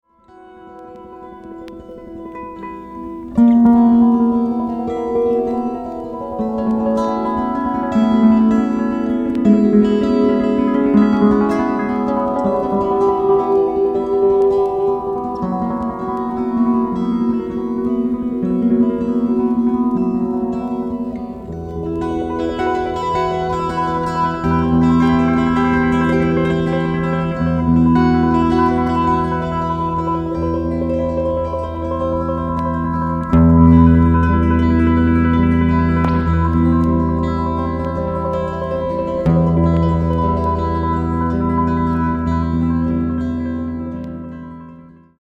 活動初期の82年にギターのデュオ編成で発表した作品。二本のギターの響きがしなやかに相交わる全5曲を収録。